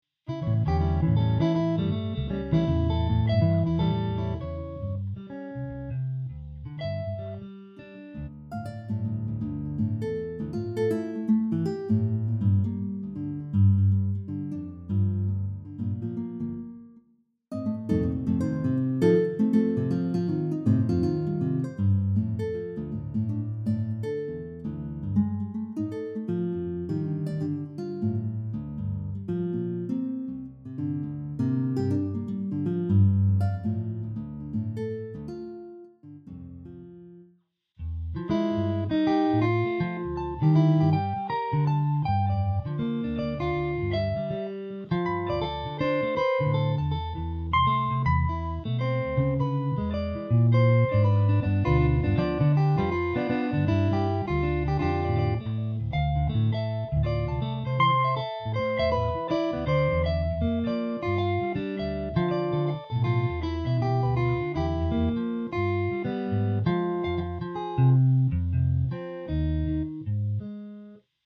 Jazz Guitar
Nylon Guitar